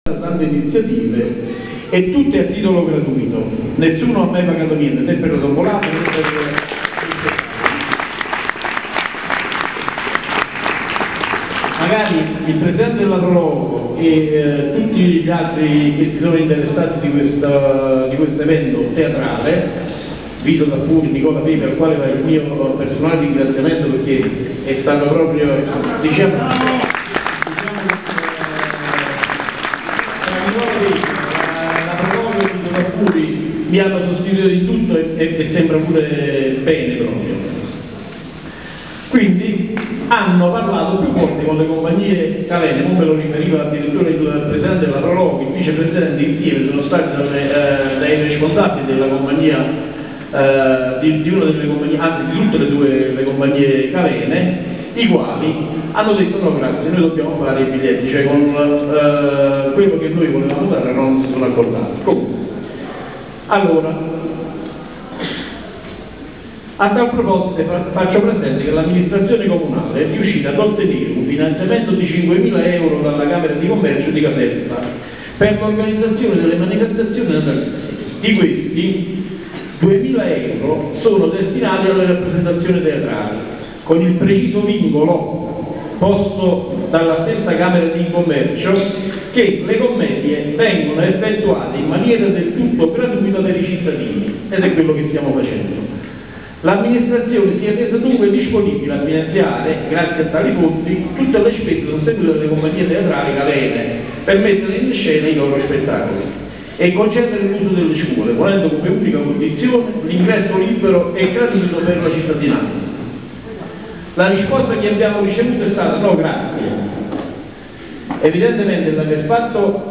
Discorso del sindaco